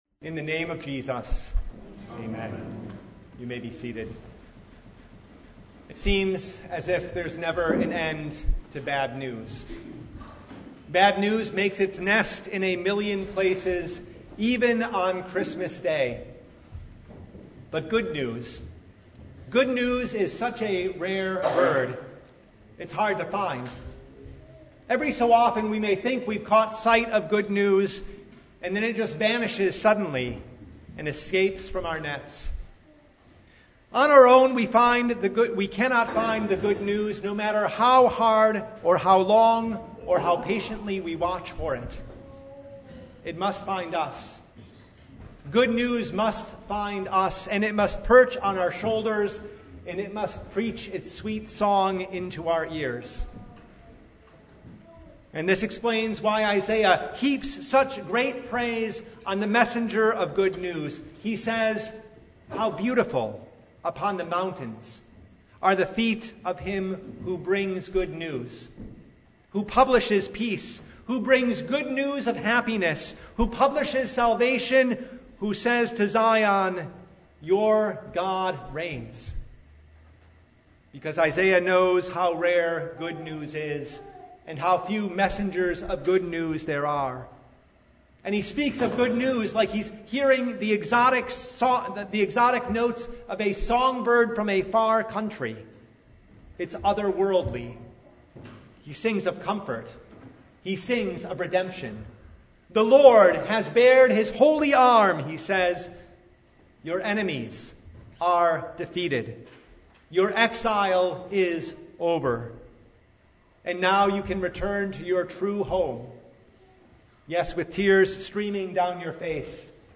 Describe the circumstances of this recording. Service Type: Christmas Day